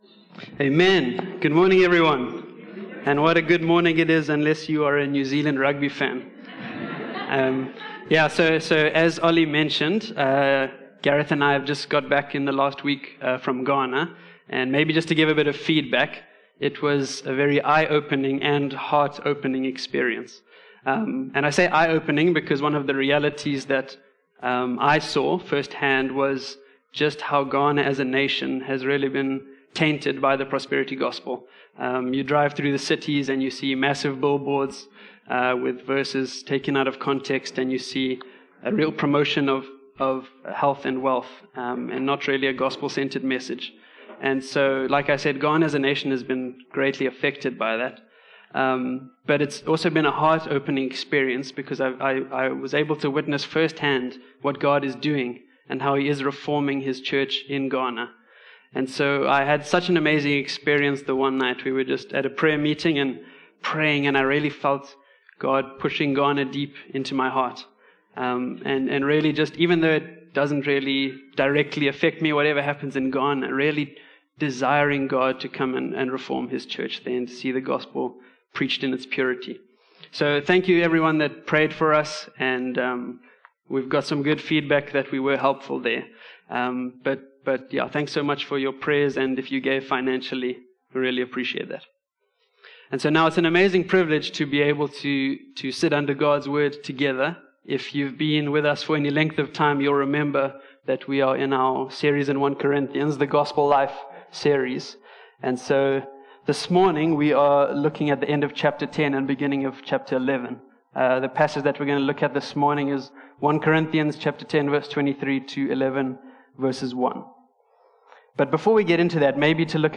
A message from the series "Gospel Life."